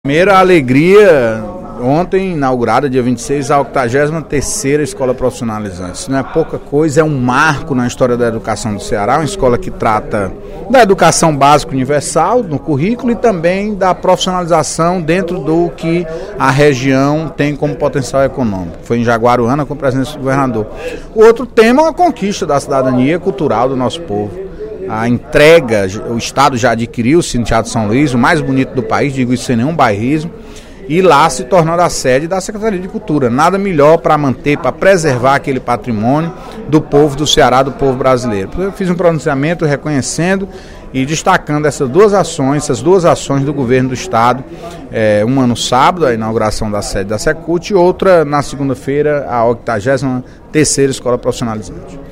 Durante pronunciamento na sessão plenária desta terça-feira (27/03), o deputado Antônio Carlos (PT) destacou a inauguração da Escola Estadual de Educação Profissional Francisca Rocha Silva, no município de Jaguaruana, na região do Baixo Jaguaribe.